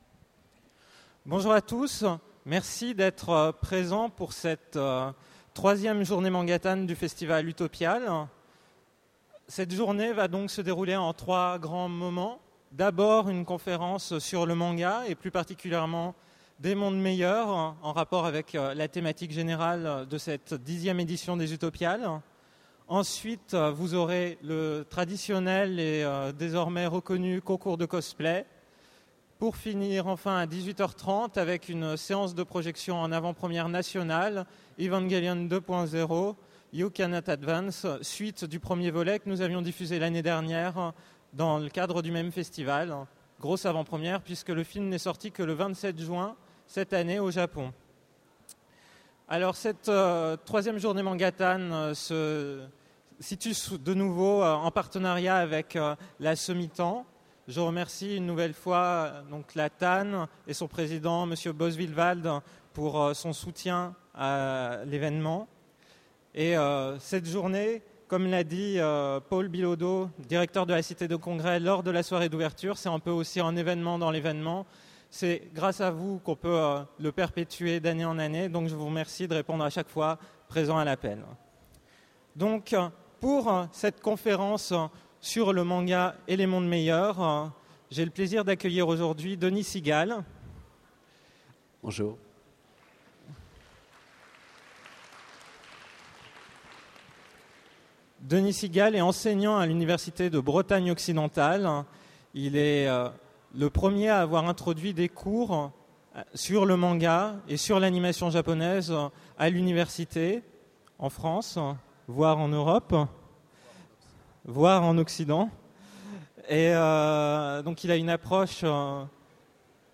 Voici l'enregistrement de la conférence autour des Mondes Meilleurs et du Manga aux Utopiales 2009.